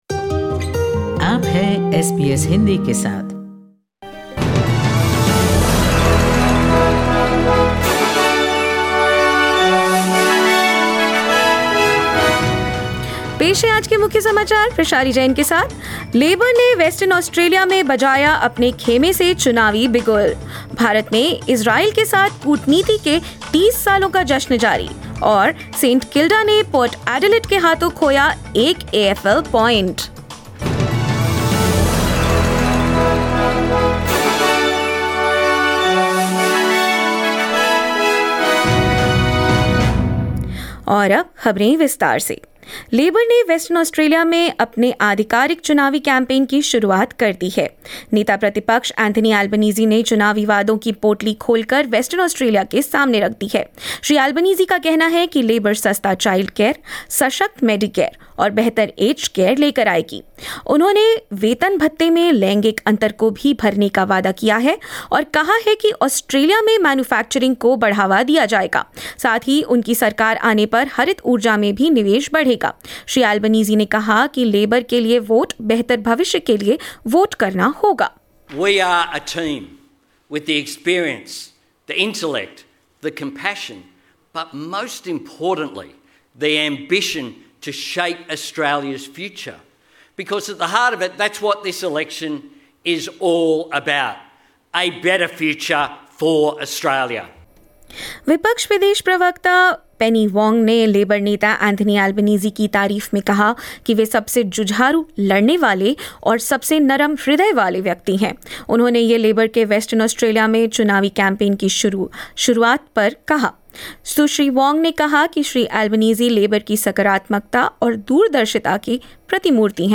In this latest SBS Hindi bulletin: Labor launches election campaign in Western Australia with promises of better aged care, cheaper childcare and affordable medicare; Coalition promises better online security for children and women, announces more investments; India celebrates 30 years of diplomatic relations with Israel, and more news.